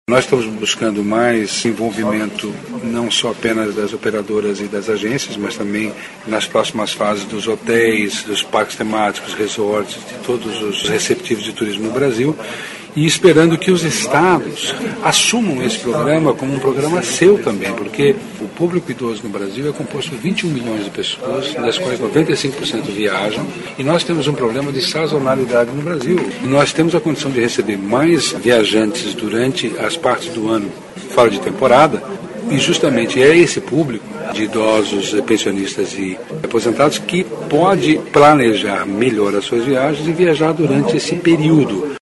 aqui e ouça declaração do secretário Vinícius Lummertz sobre a importância do Viaja Mais para movimentar o turismo interno.